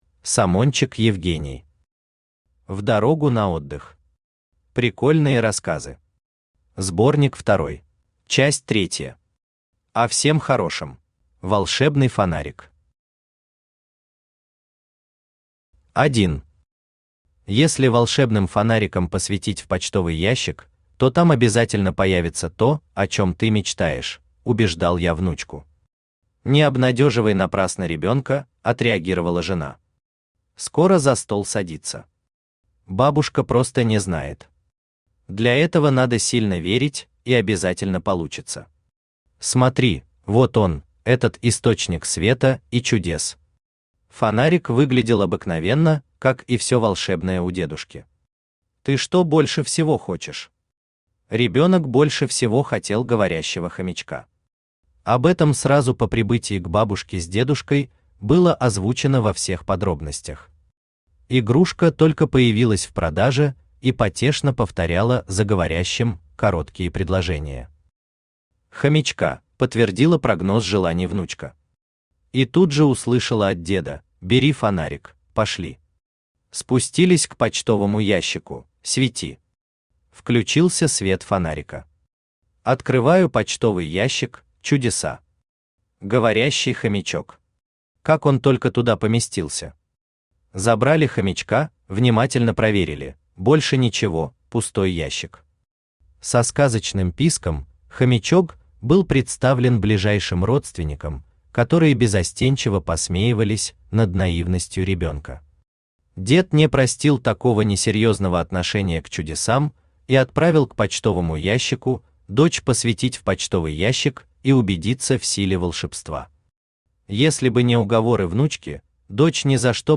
Сборник второй Автор Самончик Евгений Читает аудиокнигу Авточтец ЛитРес.